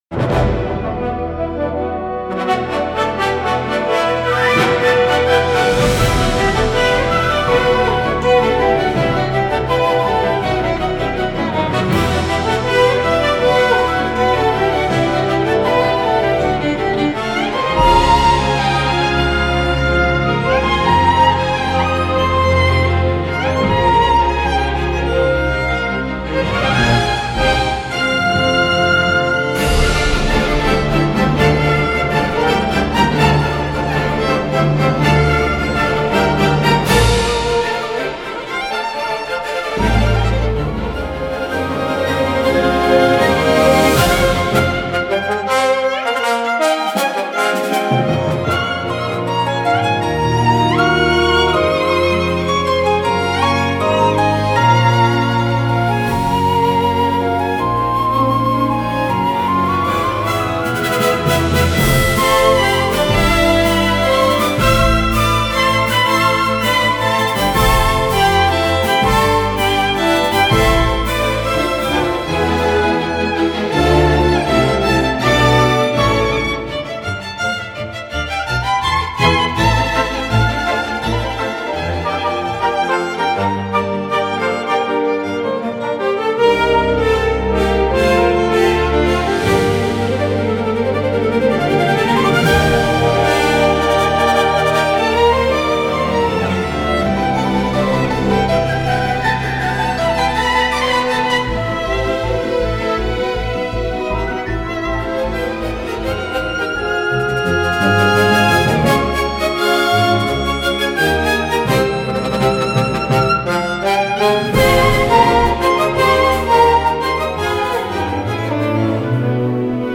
Music Demo / MP3
Violin Player
Strings Player
Piano Player
このCDは、できるだけそれに近づけつつも、私のMIXらしく、ヴァイオリンを全面に出し
本物のオーケストラでは音が一体になりすぎて、LRのステレオ感がなくなるところを、、、
そのあたりも含めて、本当に、独創的で、心に響く、オーケストラ〜バイオリン協奏曲になったのでは。